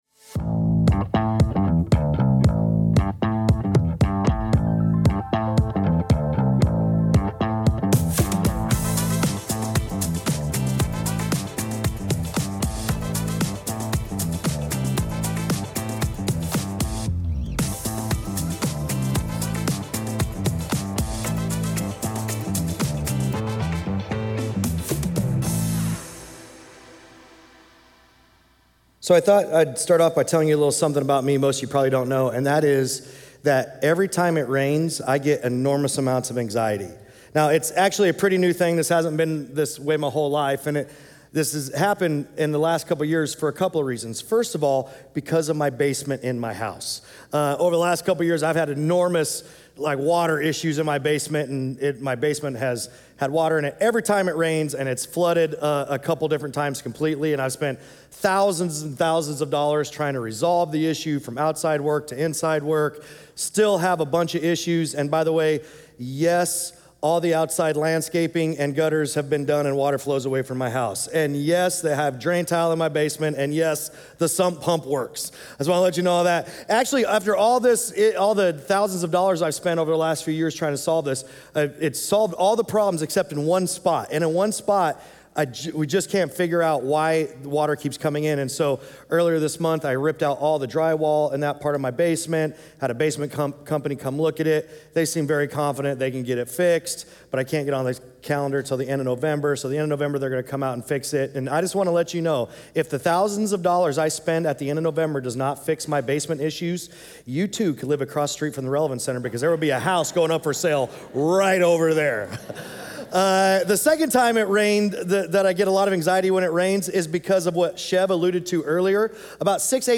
Sunday Sermons Uncommon Community, Part 4: "Love" Sep 21 2025 | 00:37:54 1x 00:00 / 00:37:54 Subscribe Share Apple Podcasts Spotify Overcast RSS Feed Share Link Embed